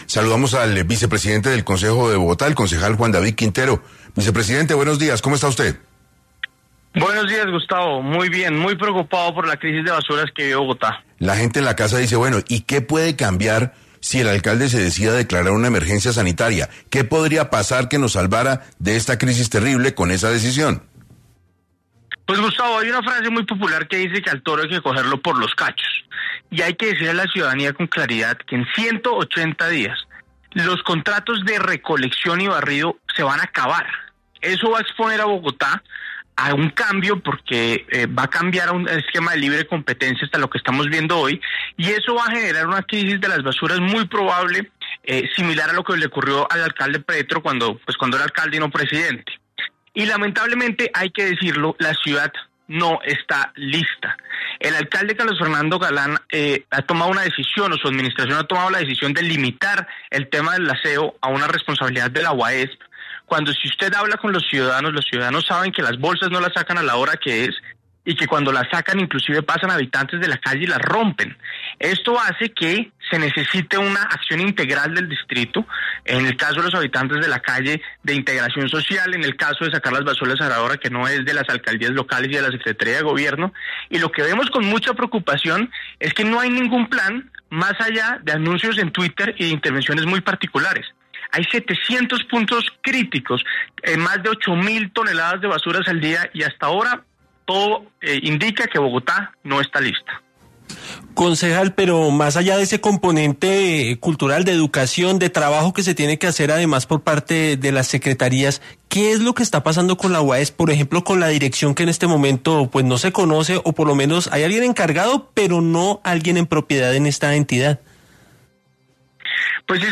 Juan David Quintero, vicepresidente del Concejo de Bogotá, hace un llamado en 6AM al alcalde Carlos Fernando Galán a atender la crisis en la capital por la acumulación de basuras.